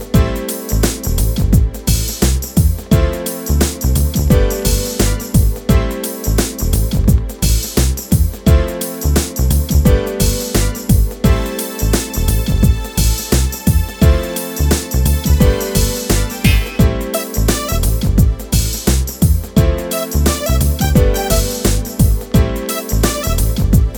Radio Edit with No Backing Vocals Pop (1990s) 3:49 Buy £1.50